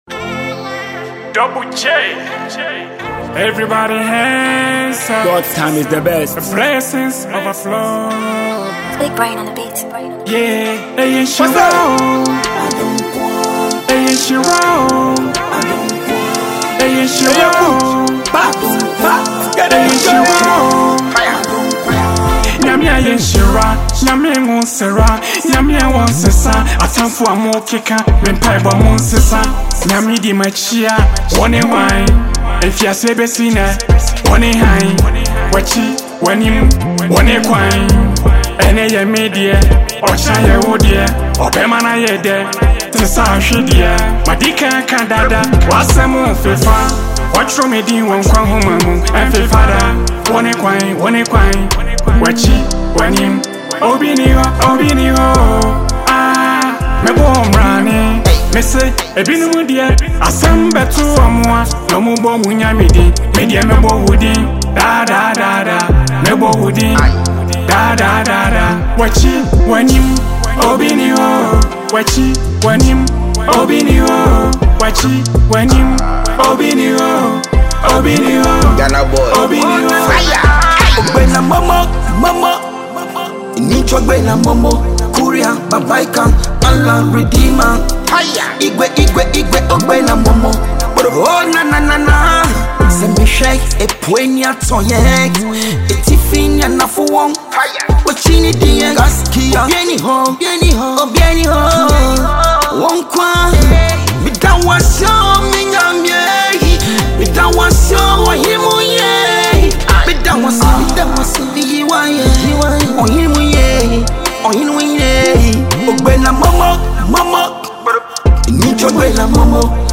love song
smooth and soulful vocals